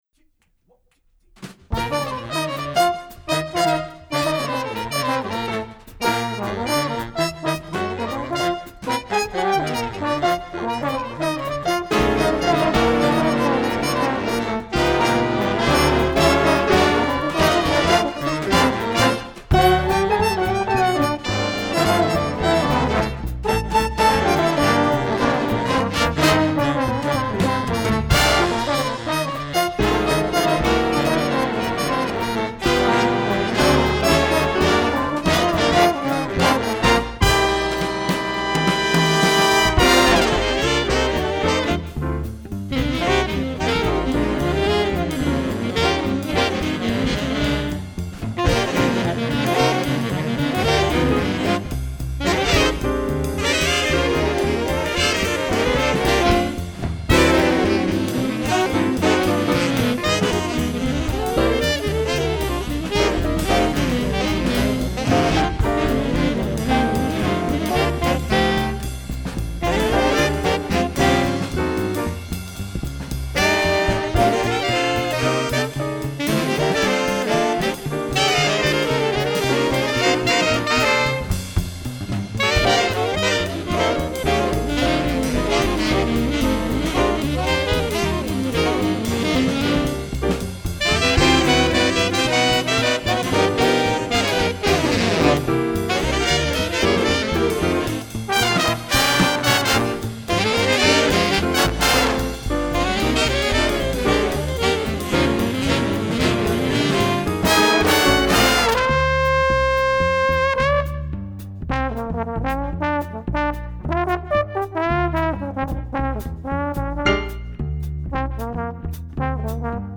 MVHS JAZZ ENSEMBLE PROJECTS BY YEAR
trumpet
Bari sax